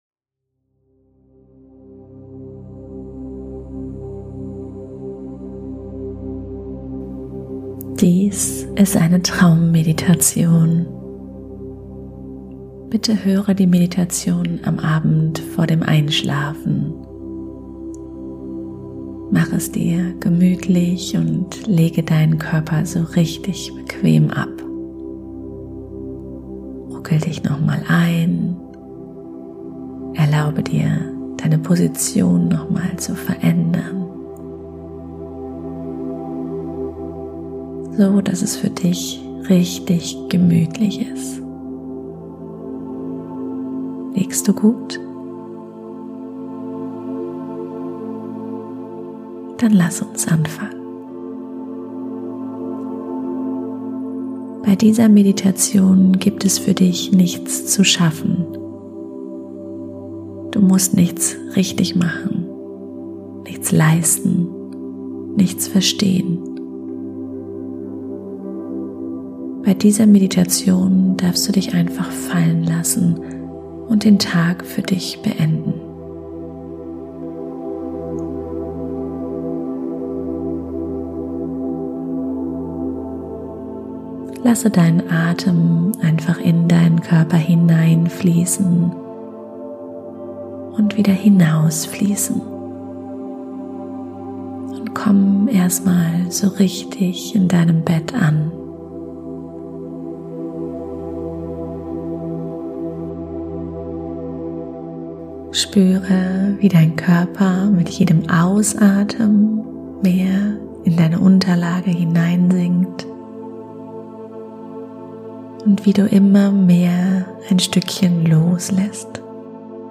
Dies ist eine Meditation die dir hilft die Gedanken und den Alltag loszulassen und in Frieden einzuschlafen.